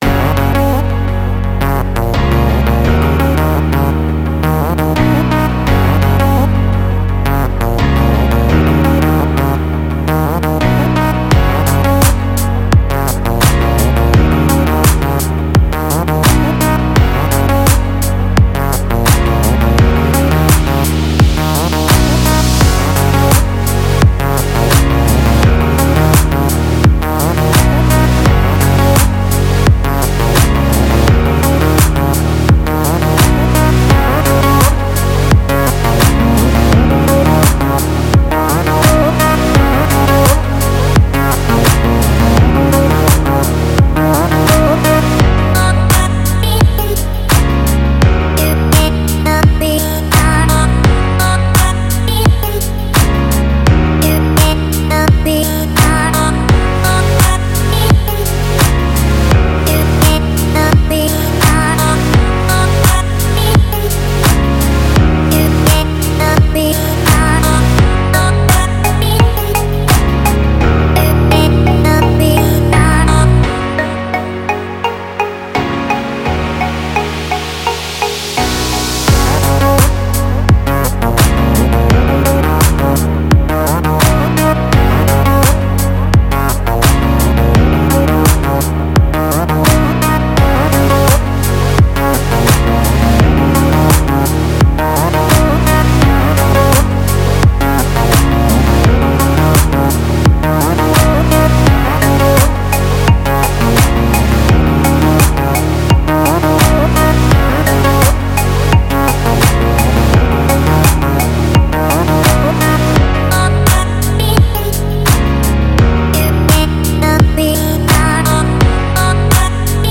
это захватывающая композиция в жанре электронной музыки